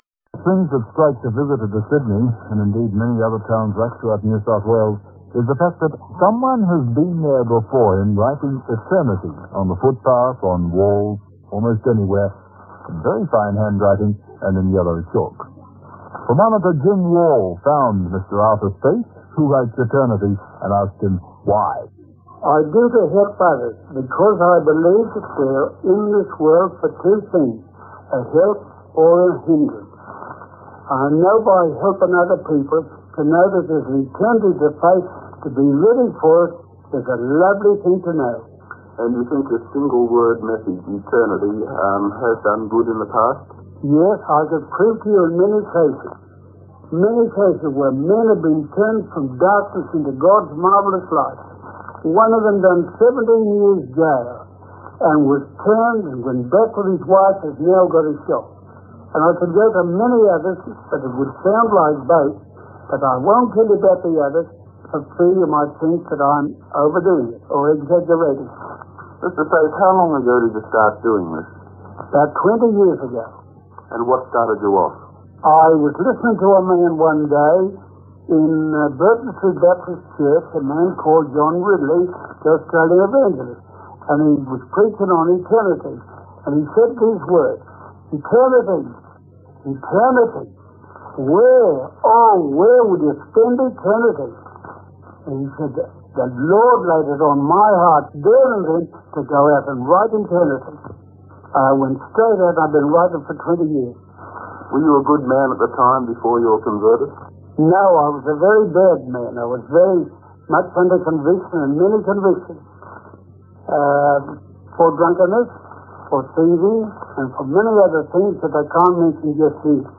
A radio interview with Arthur Stace, conducted in 1964 when he was 79, is